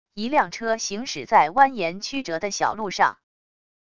一辆车行驶在蜿蜒曲折的小路上wav音频